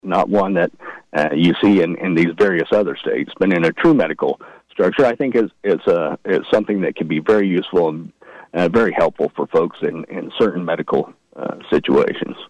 Smith joined KVOE’s Morning Show Thursday to conclude KVOE’s annual legislative previews and says when it comes to the budget, he is pleased with where the state stands, especially after December tax collections topped estimates by almost 15 percent.